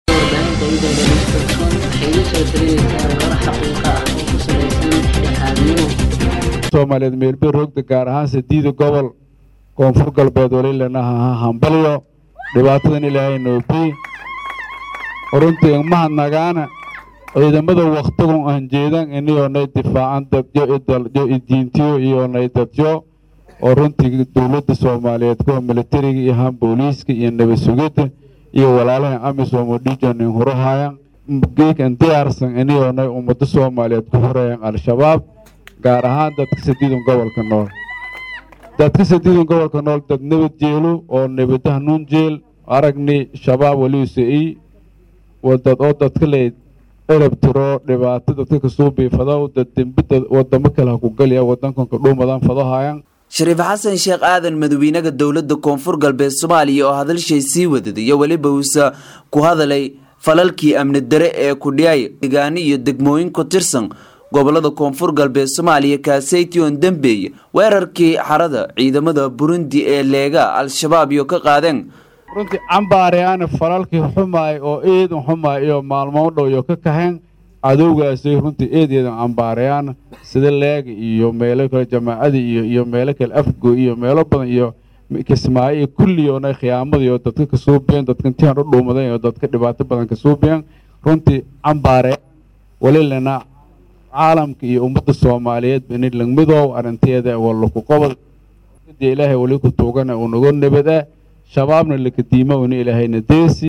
Dhageyso: Khudbadii Madaxweynaha KGS Uu Xaley Ka Jeediyey Xuska 1da Luulyo
Baydhabo(INO)-Xarunta Madaxtooyadda KGS Ee Baydhabo waxaa habeenkii xalay ahaa lagu qabtay munaasabad ballaaran oo loogu dabaal degaayay 55 Sano guuradii kasoo wareegtay markii ay Gumeysiga Talyaaniga ka xuroobeen gobalada Koonfureed ee dalka, islamarkaana ay midoobeen gobalada Waqooyi iyo Koonfur ee Soomaaliya.
Madaxweynaha Dowladda KGS Soomaaliya Mudane Shariif Xasan Sheekh Aada oo halkaasi ka hadlay ayaa shacabka Soomaaliyeed waxa uu ugu hambalyeeyay munaasabadda 1-da Luuliyo, Gaar ahaan Kuwa KG  Soomaaliya  isaga oo ka hadlay qiimaha maalintaan ay leedahay iyo muhiimadda midowga umadda Soomaaliyeed.
khubadda-madaxweyne-shariif-xasan.mp3